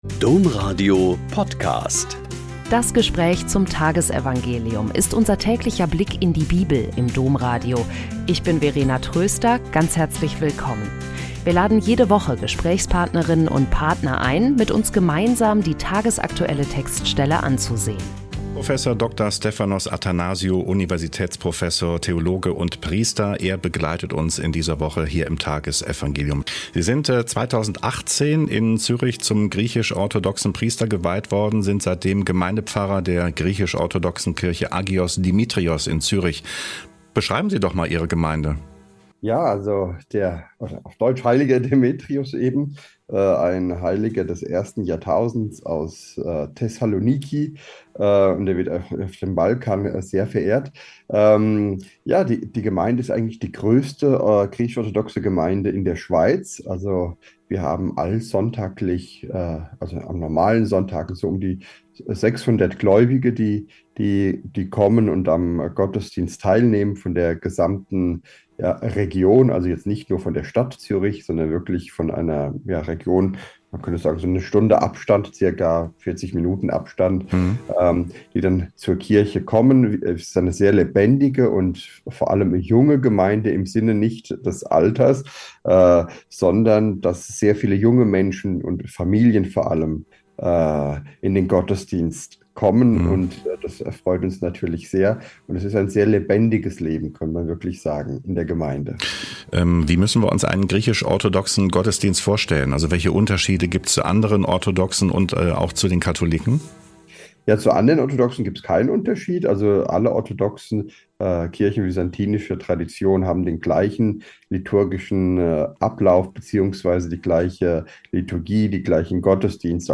Mt 22,1-14 - Gespräch